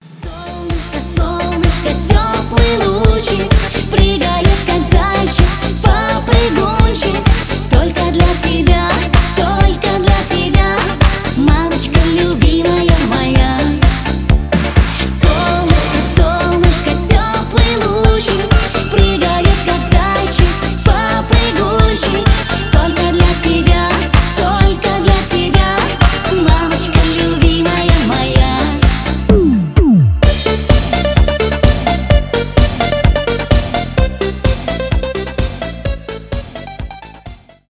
ремиксы , танцевальные , диско
поп